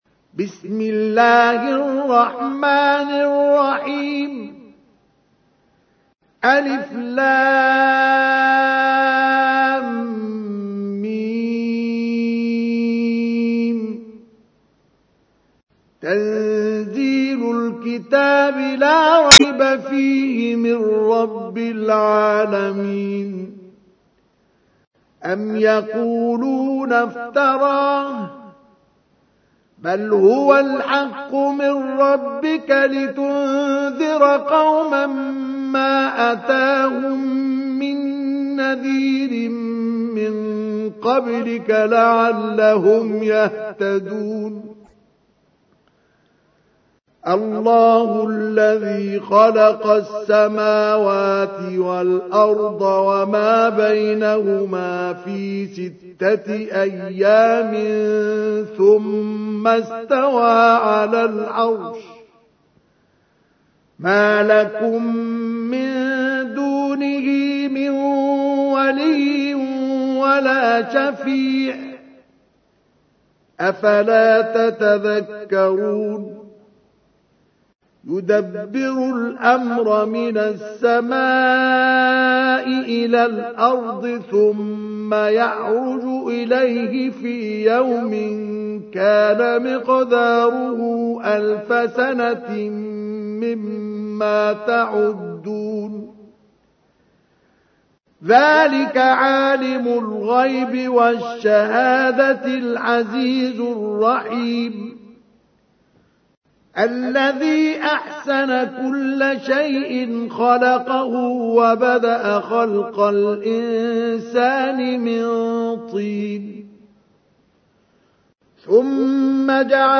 تحميل : 32. سورة السجدة / القارئ مصطفى اسماعيل / القرآن الكريم / موقع يا حسين